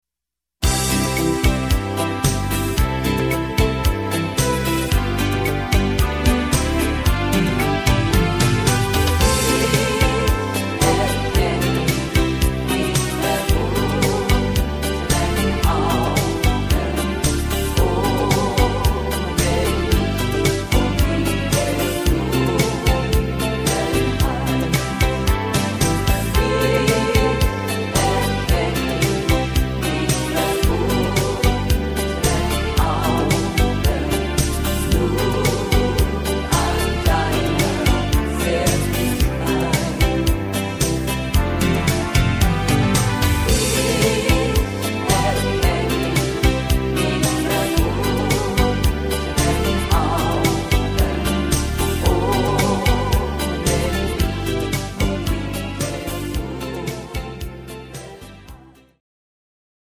Rhythmus  Beguine
Art  Deutsch, Medleys, Oldies, Schlager 60er